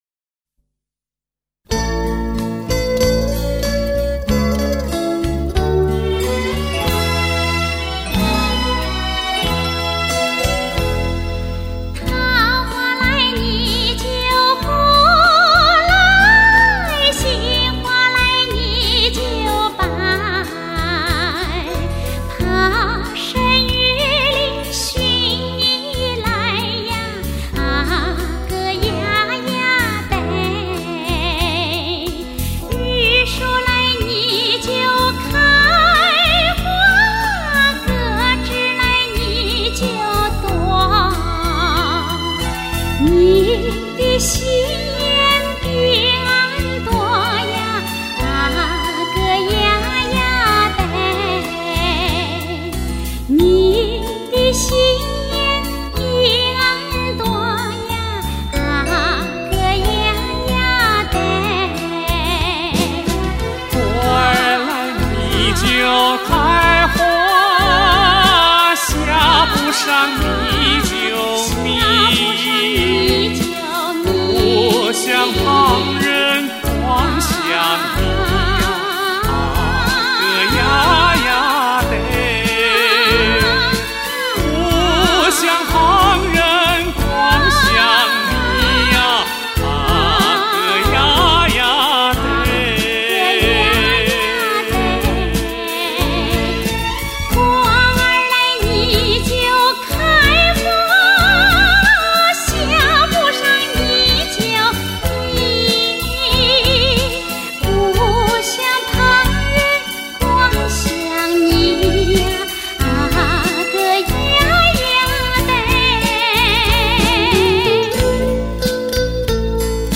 [2006-9-2]原汁原味的纯美山西左权民歌--桃花红 杏花白